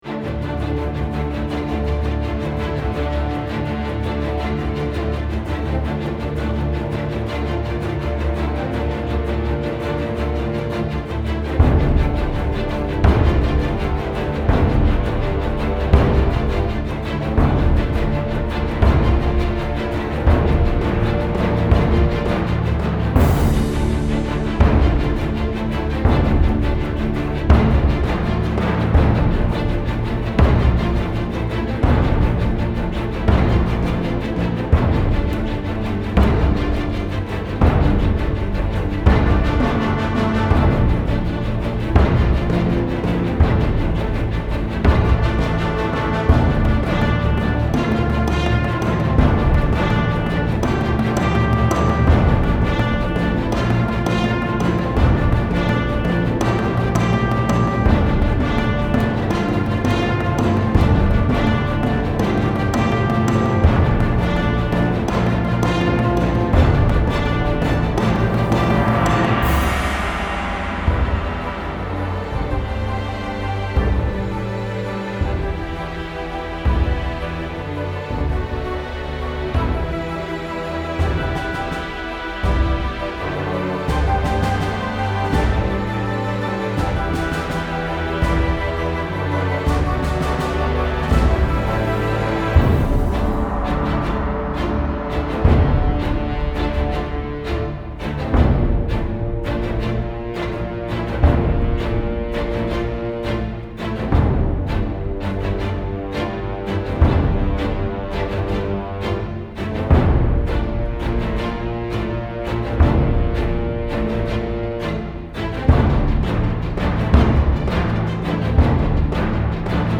Style Style Orchestral, Soundtrack
Mood Mood Epic, Intense
Featured Featured Brass, Choir, Percussion +2 more
BPM BPM 166
Epic orchestral music with a full choir.